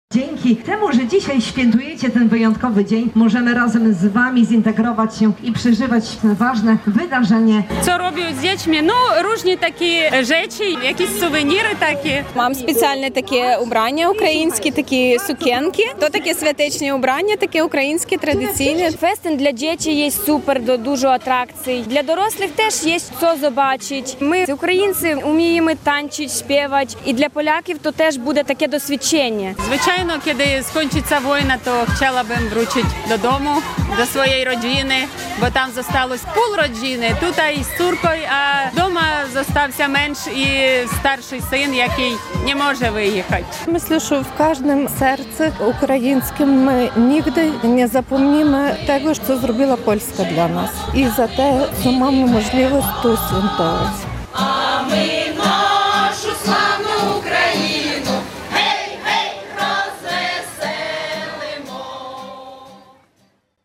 relacja
Impreza przypada w Dniu Niepodległości Ukrainy, miała więc dużo ukraińskich akcentów.